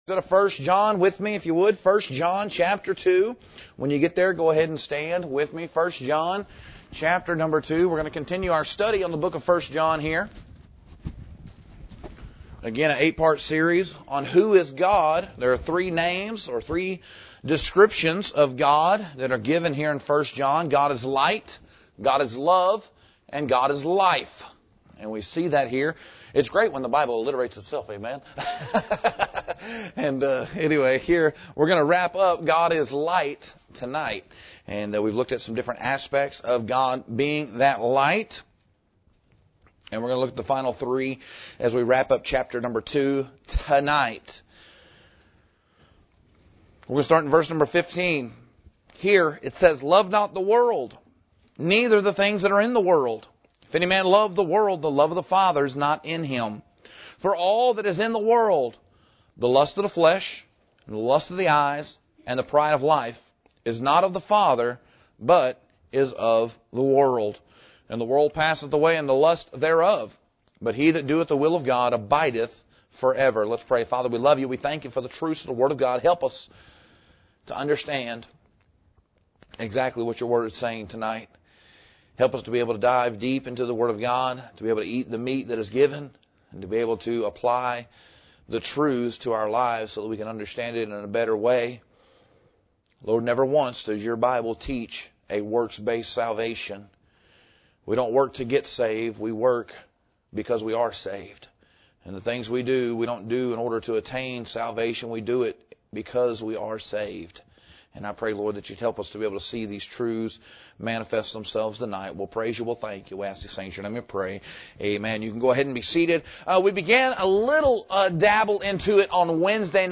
This sermon examines how God’s light exposes the world, advises the believer, and enables us to abide in Christ. The passage highlights three temptations of the world— the lust of the flesh, the lust of the eyes, and the pride of life— and shows how the Word and Spirit guide, protect, and mature the child of God.